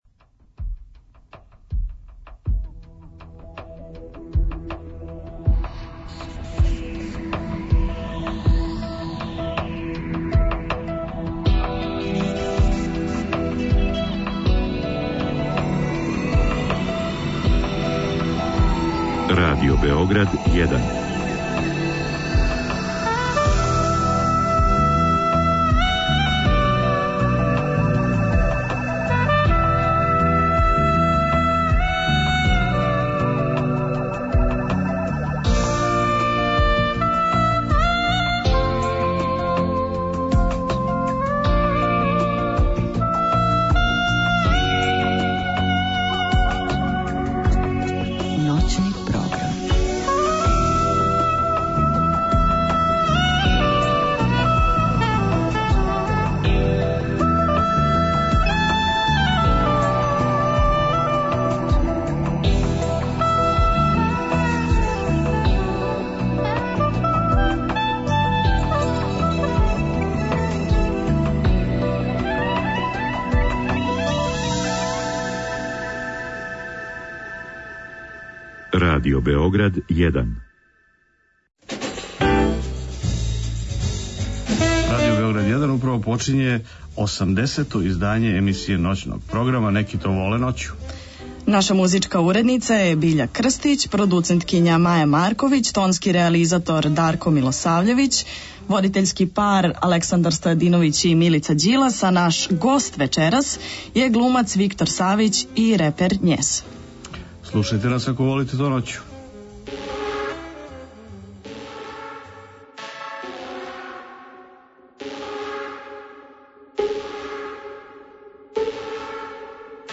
Наш гост је глумац Виктор Савић.